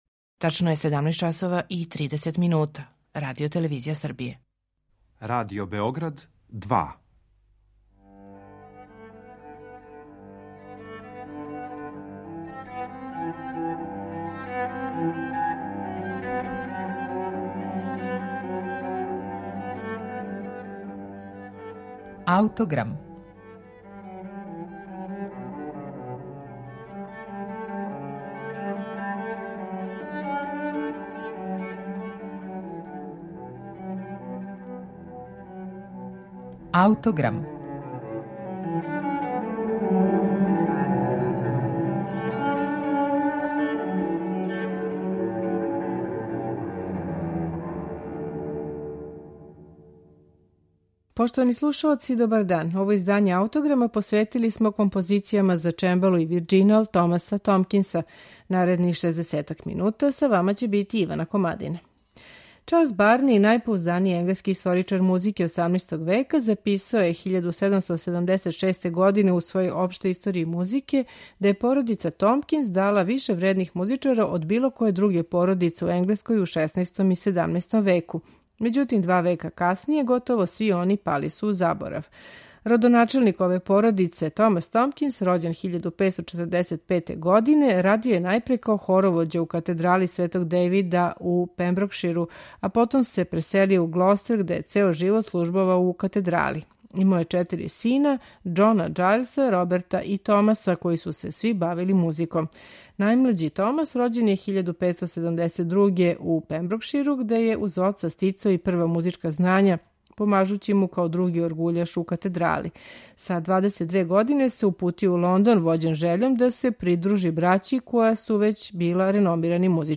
Једанаест Томкинсових композиција за чембало и вирџинал